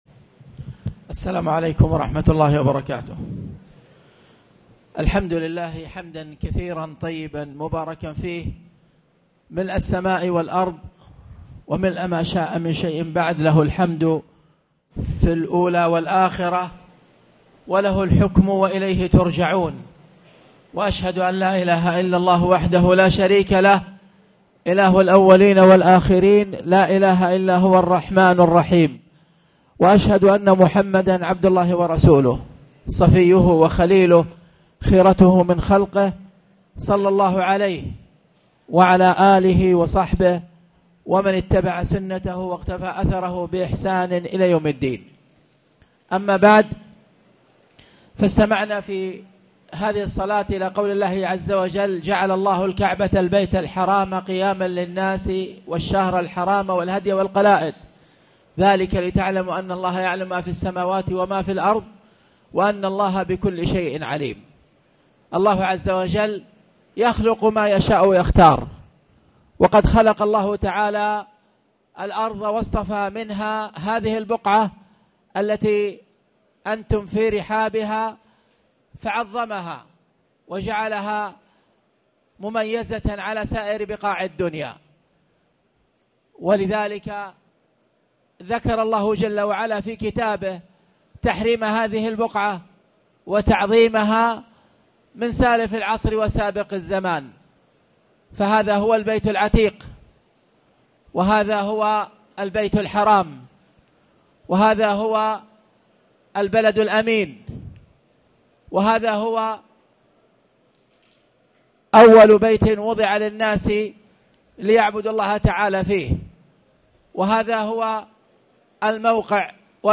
تاريخ النشر ٣ ذو الحجة ١٤٣٨ هـ المكان: المسجد الحرام الشيخ